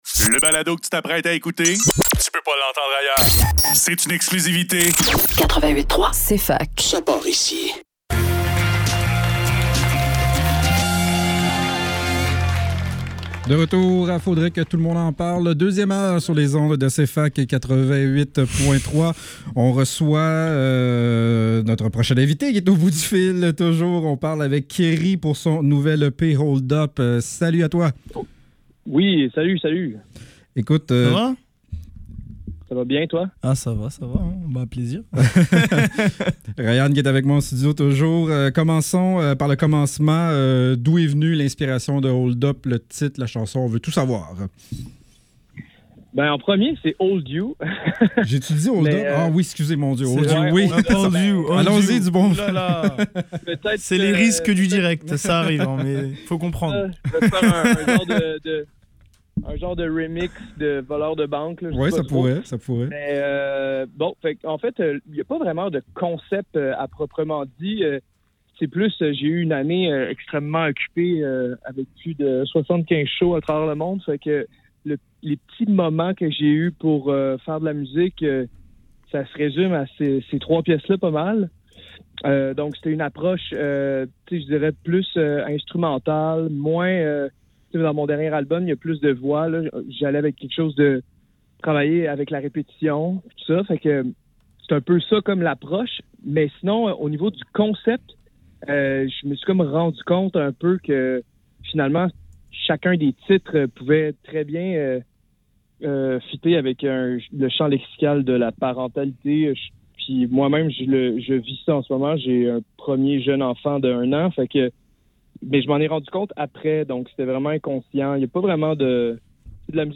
Faudrait que tout l'monde en parle - Entrevue avec CRi - 24 septembre 2024